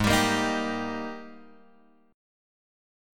G# Major 7th Suspended 2nd